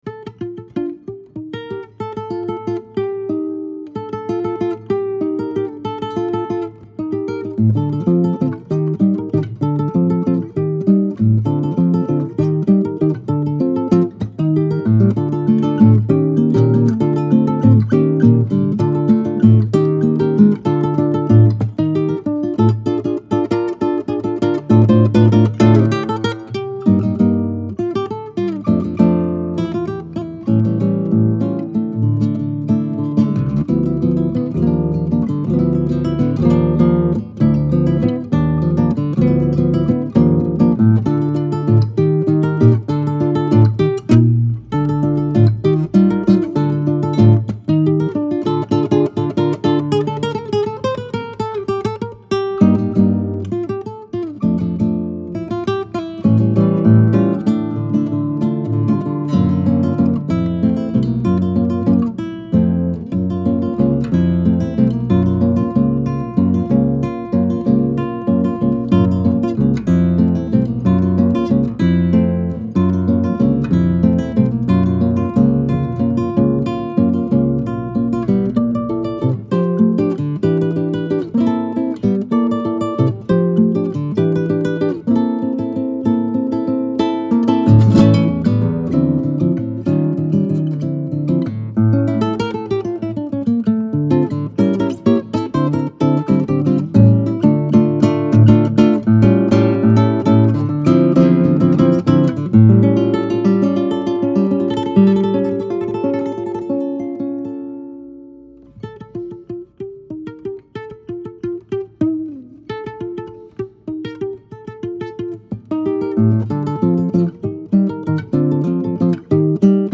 inspired by Jazz and Latin-American popular music